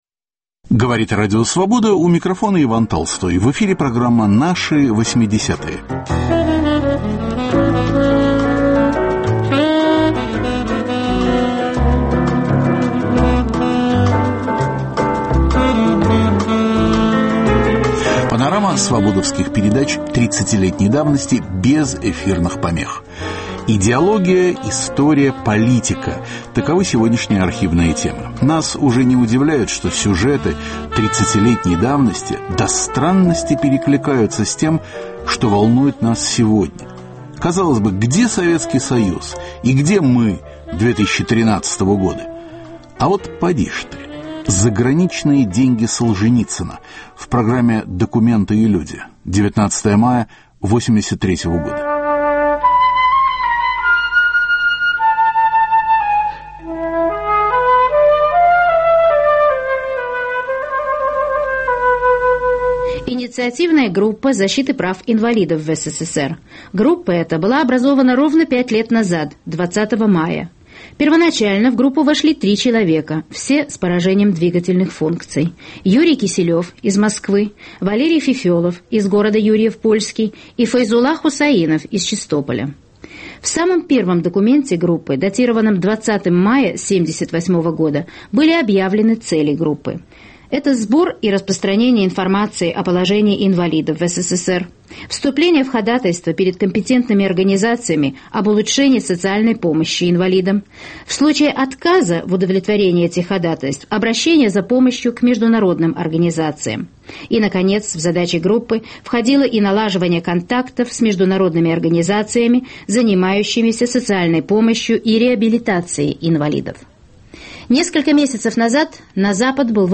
Наши 80-е: архивные передачи впервые без глушения. Заграничные деньги Солженицына, Эренбургговские трубки, советский чиновник в заложниках у душманов.